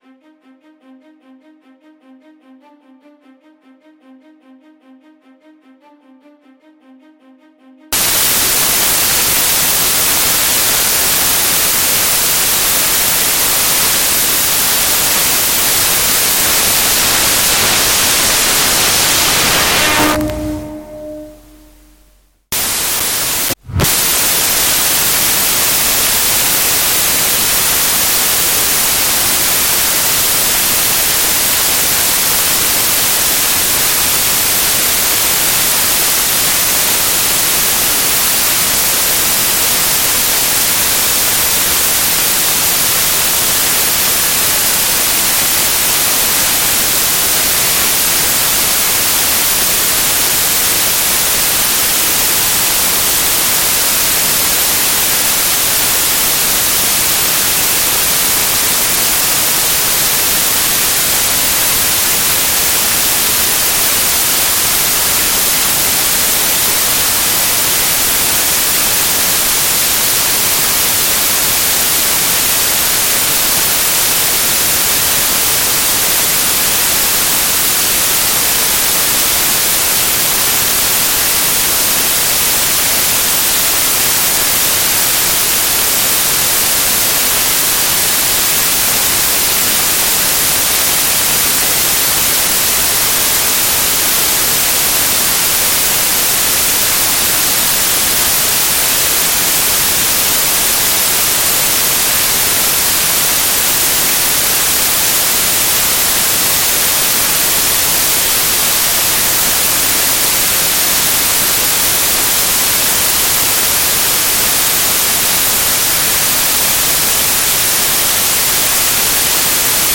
Simply play the song to discover the painful static sounds.
:warning: Warning: sound is extremely loud 10 seconds in